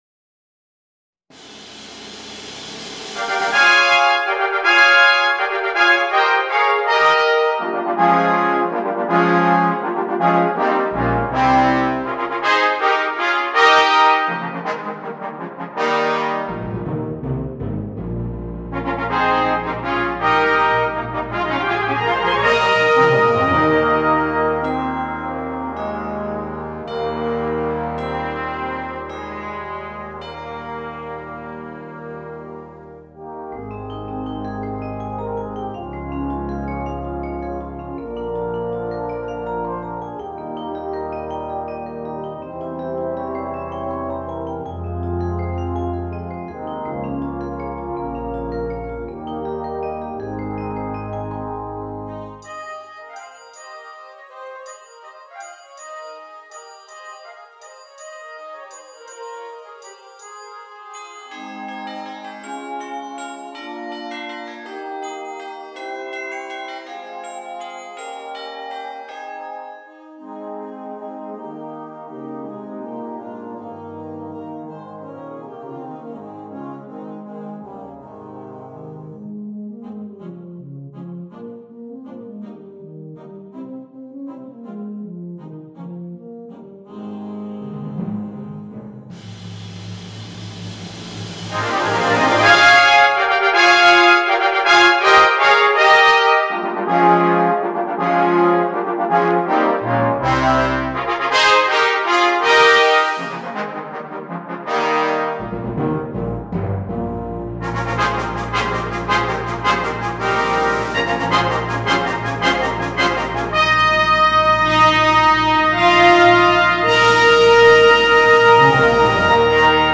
2. Brass Band
sans instrument solo
Musique de Noël